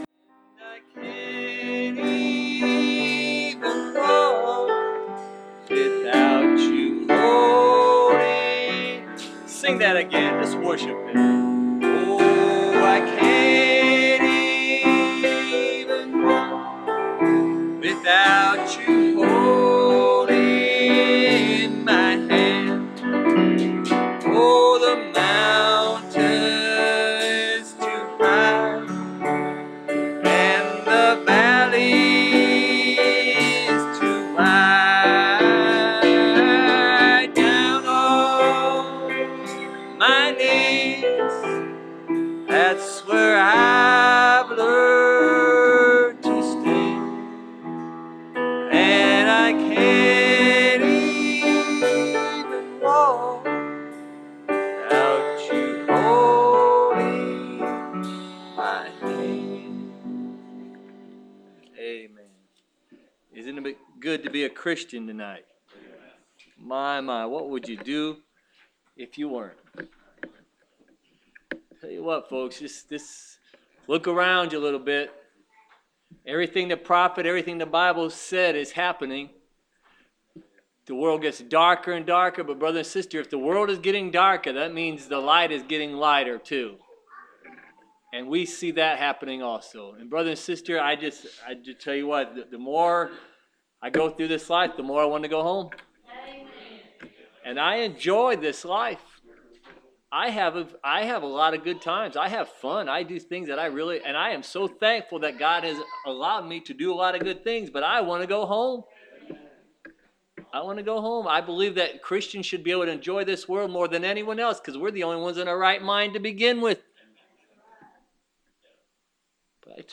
Preached April 14, 2016